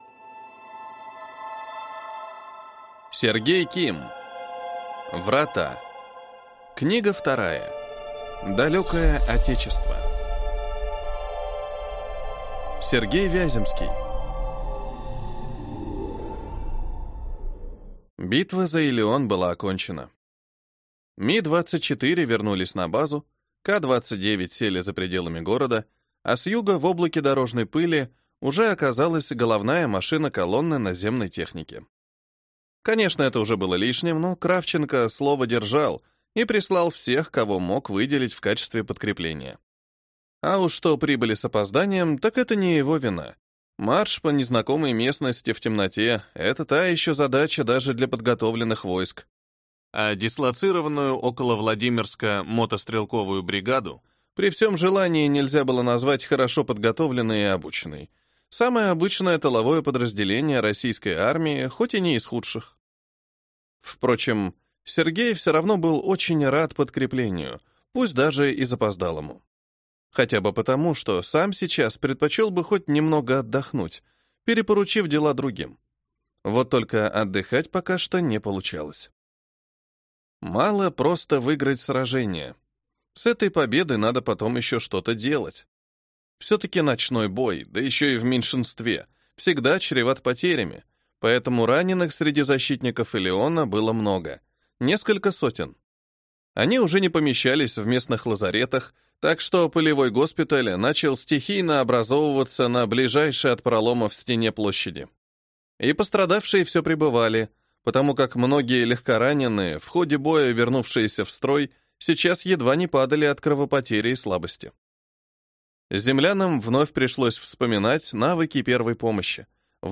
Аудиокнига Врата. Книга 2. Далёкое Отечество | Библиотека аудиокниг